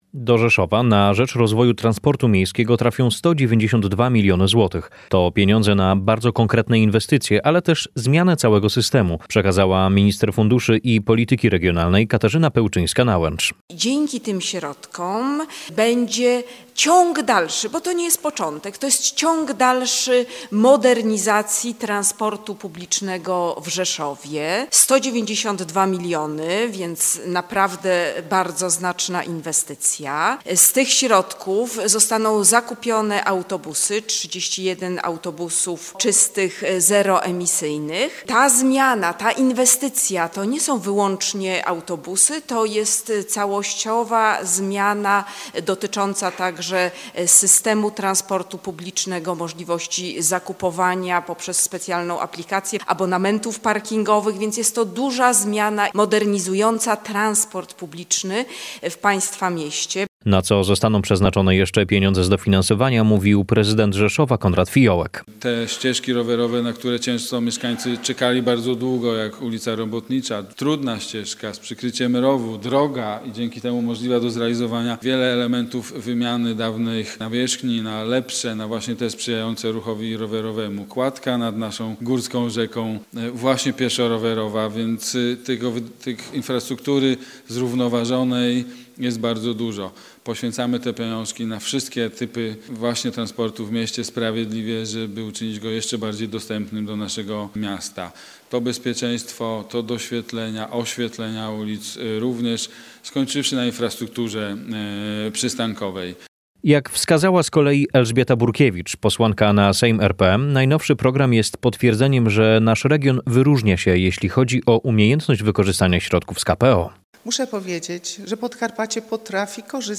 – wskazywała podczas konferencji w rzeszowskim ratuszu Minister Funduszy i Polityki Regionalnej Katarzyna Pełczyńska-Nałęcz.